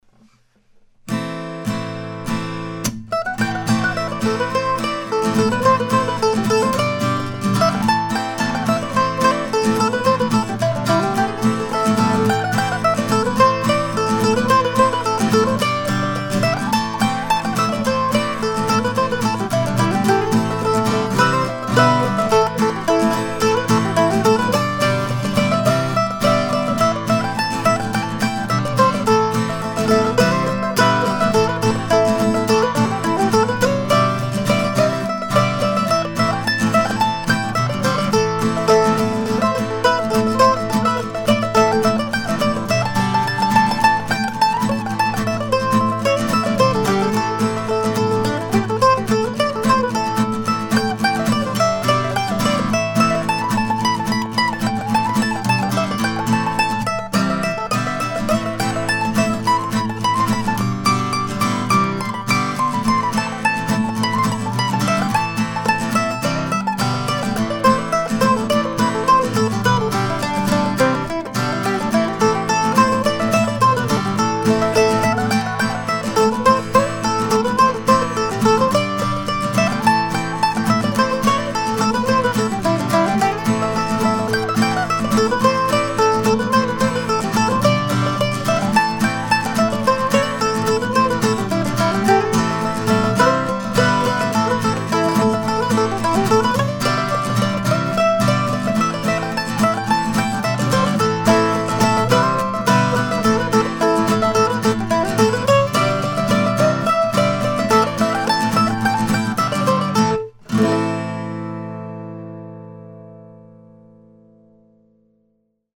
This week's tune is a fairly straightforward reel in Am.
Recorded in short order with just guitar and mandolin last Saturday.